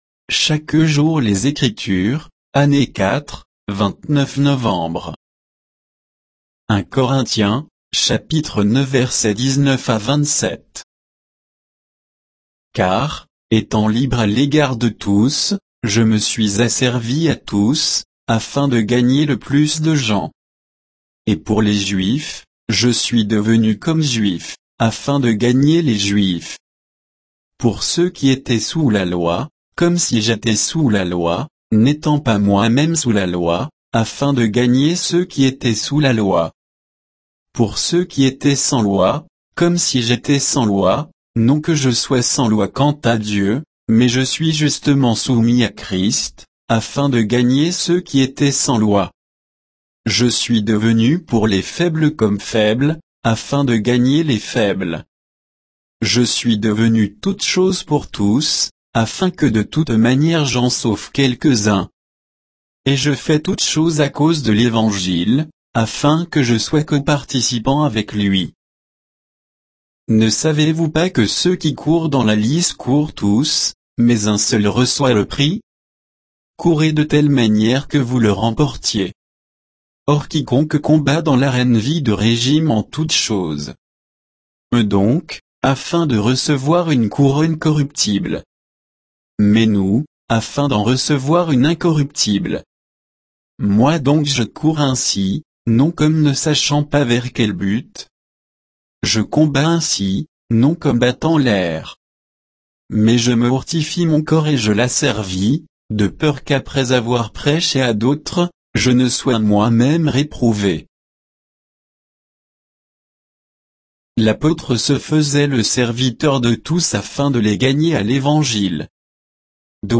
Méditation quoditienne de Chaque jour les Écritures sur 1 Corinthiens 9